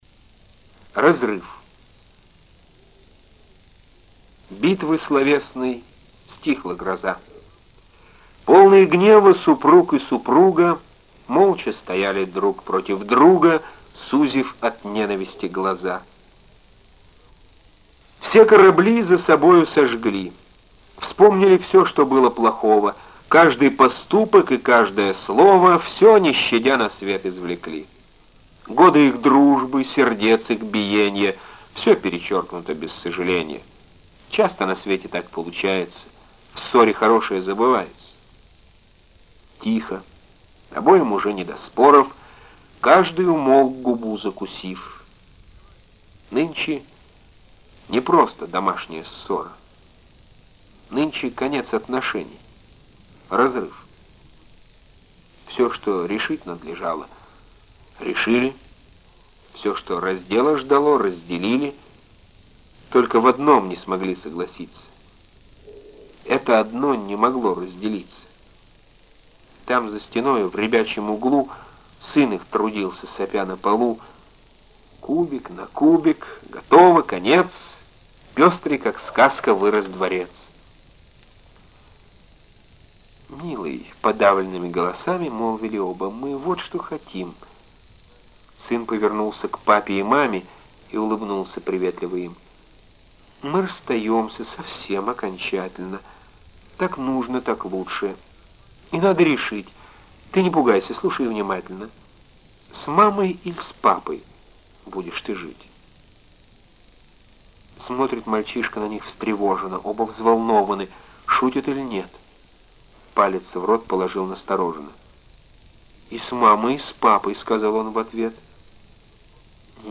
9. «Эдуард Асадов (стихи) – Разрыв (исп. Владимир Самойлов)» /
Asadov-stihi-Razryv-isp.-Vladimir-Samojlov-stih-club-ru.mp3